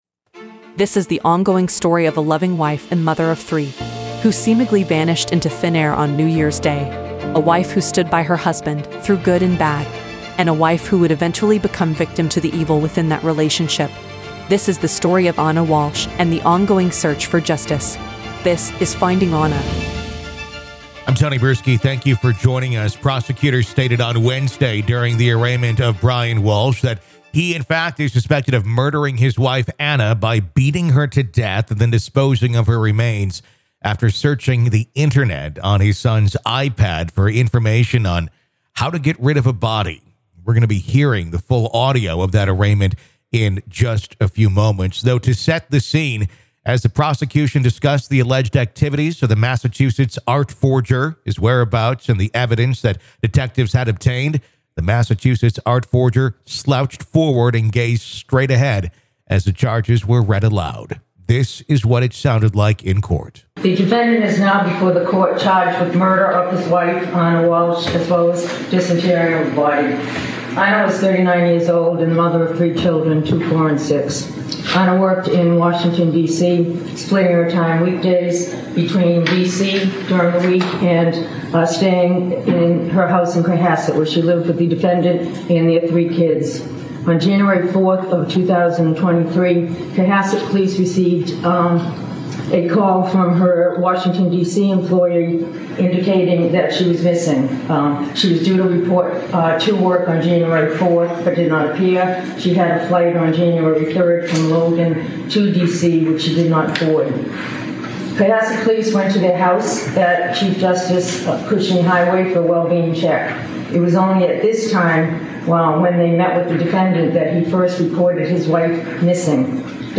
Listen to the entire arraignment in the podcast.